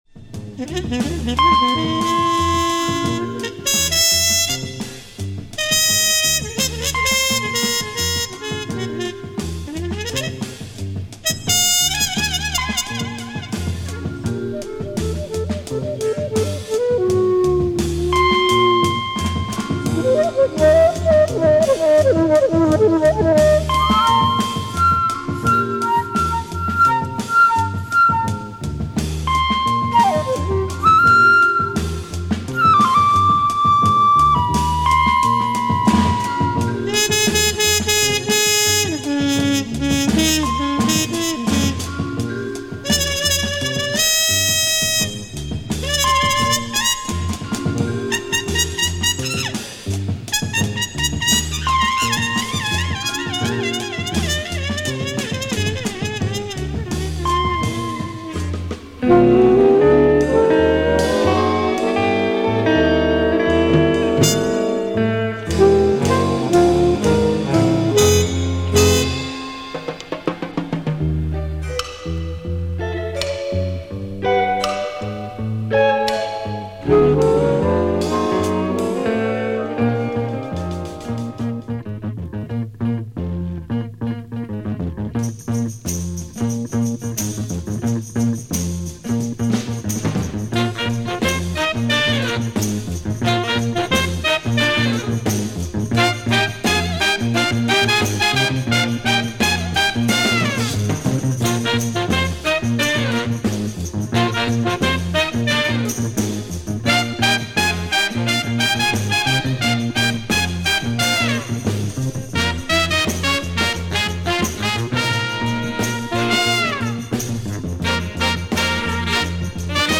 Cinematic groove, jazz and latin sounds, a real soundtrack.